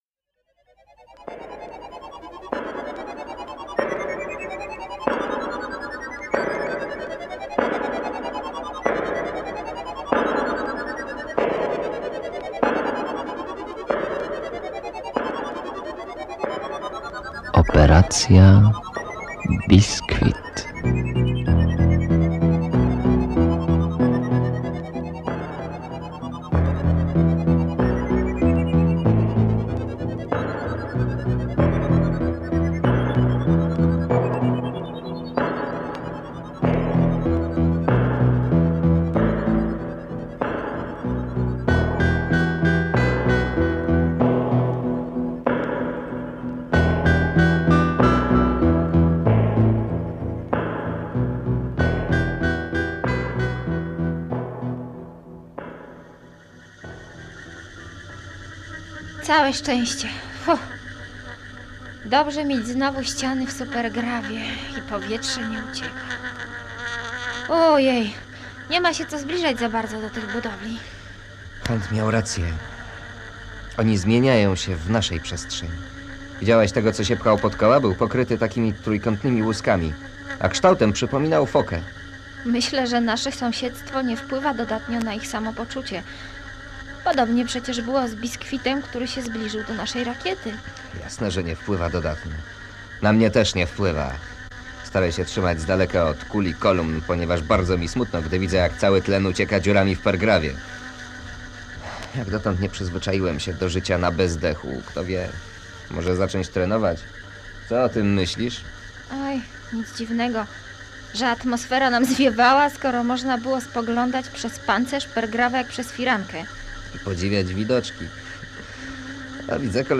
Słuchowisko science-fiction w konwencji rozrywkowej